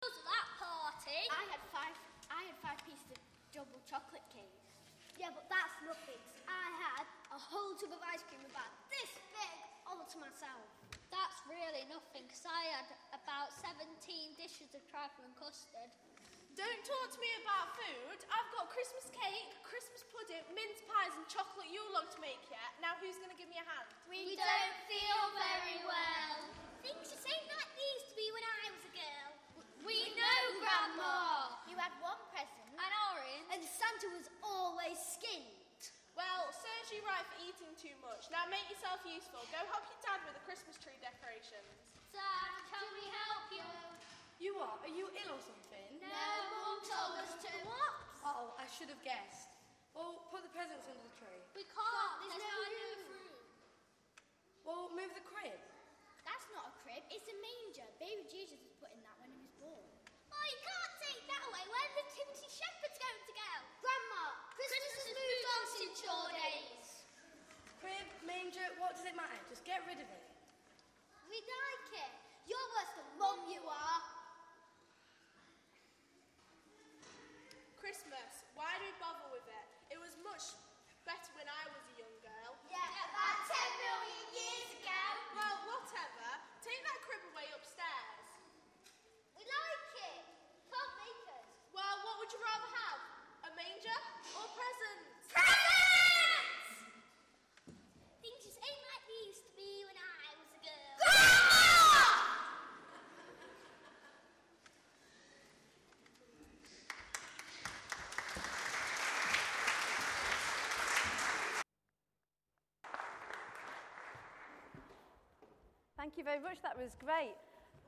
Further highlights of the All Age Nativity Service
Talk by in 10:30 Morning Worship, St John's service